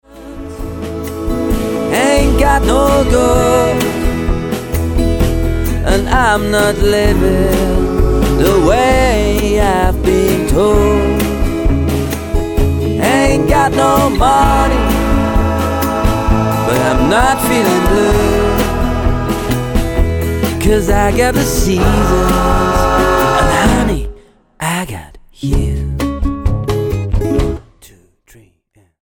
A Swiss singer/songwriter
Style: Rock